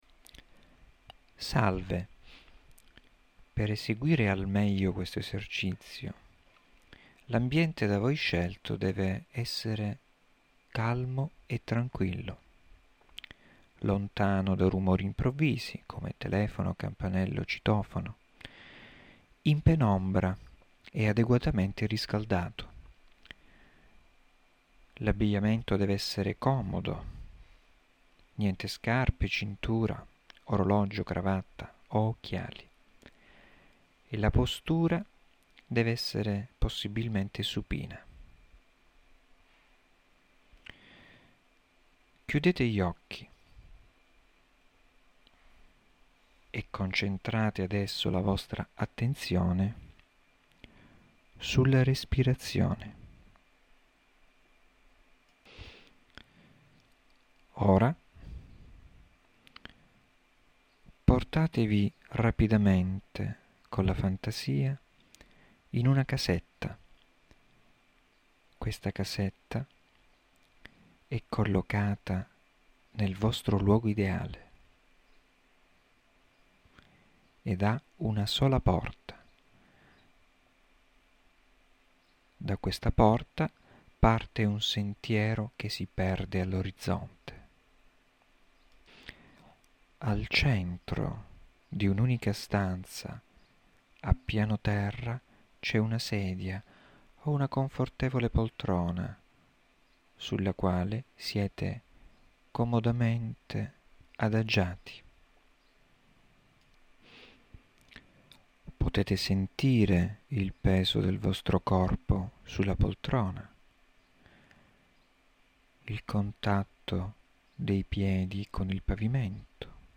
Esercizi di rilassamento
in questa area troverà alcuni esercizi di rilassamento da me registrati, comprendenti una induzione iniziale, una suggestione ed una visualizzazione (fantasia guidata).
• Secondo esempio di induzione, Sagoma.